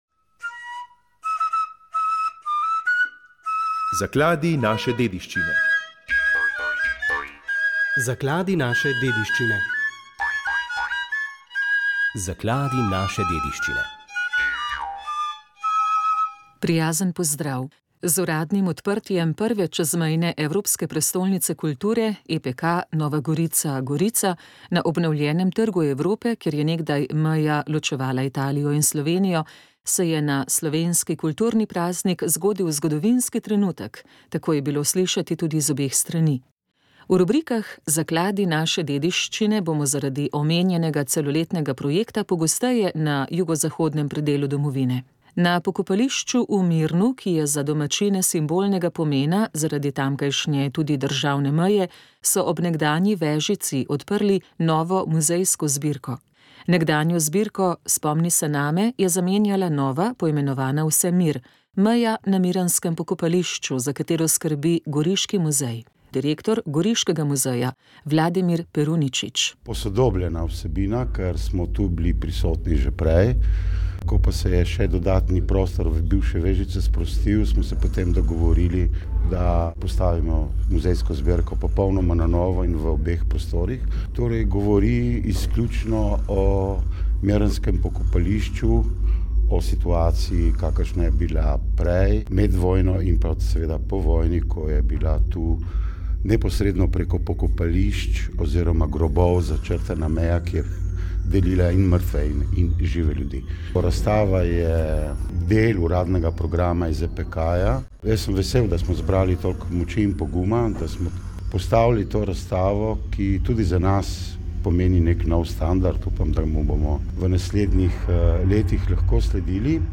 Obudili smo spomin na literarno zapuščino Ljubke Šorli. V počastitev obletnice njenega rojstva je skupaj zapelo krstne izvedbe nekaterih njenih uglasbenih pesmi več kot dvesto pevcev.